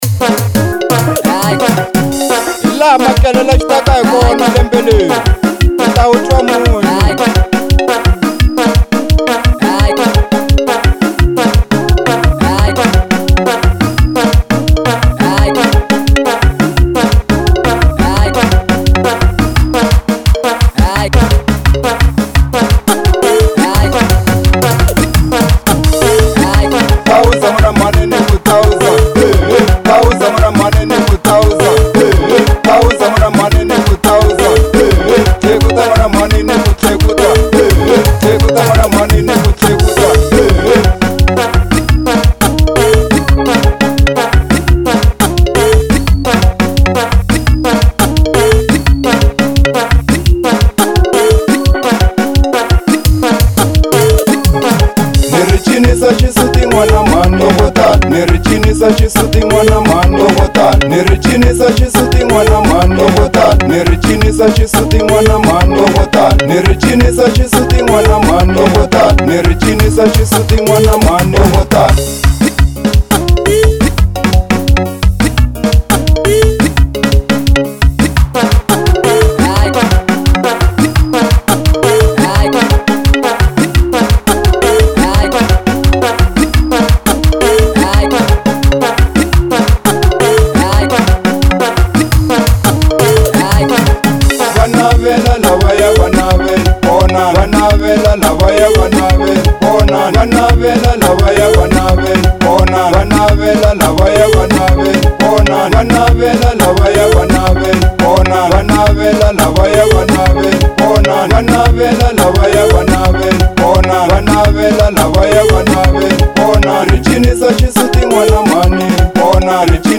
05:34 Genre : Xitsonga Size